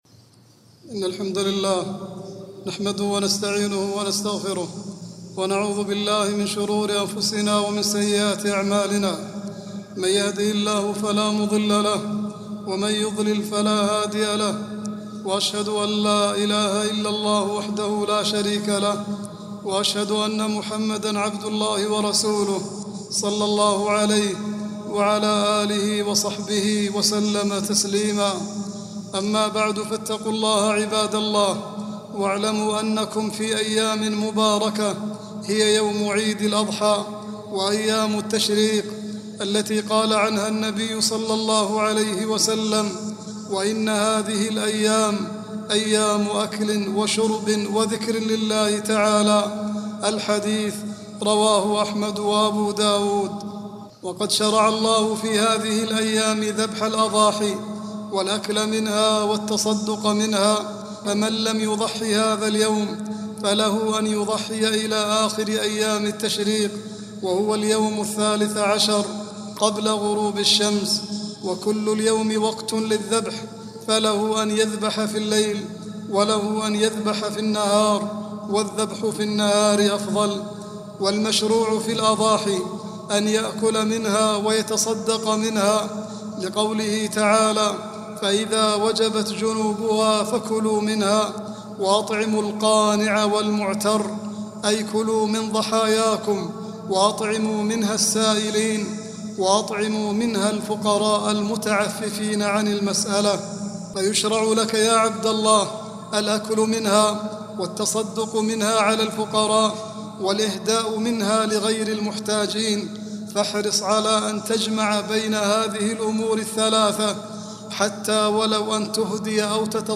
العنوان : خطبة مناسبة للجمعة في يوم عيد الأضحى